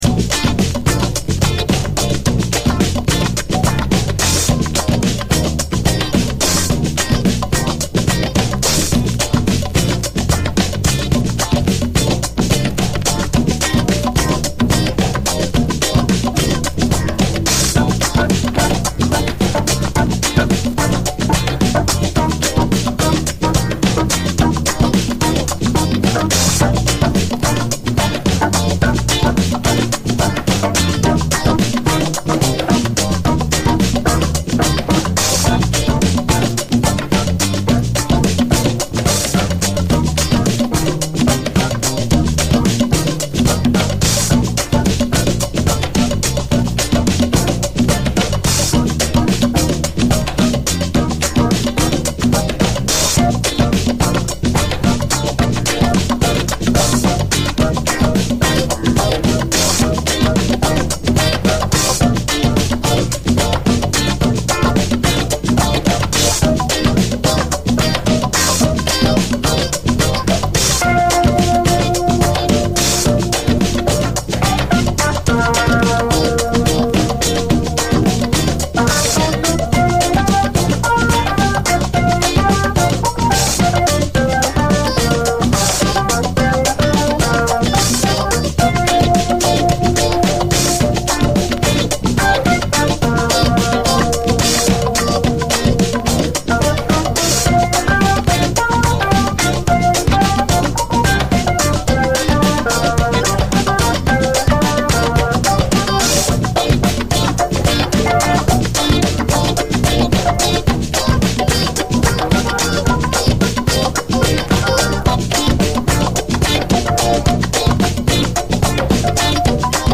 組曲として完成されており、次々と飛び出すグルーヴの連続は圧巻です！
幸福感で満たすブラスのミディアム・ラテン・ダンサー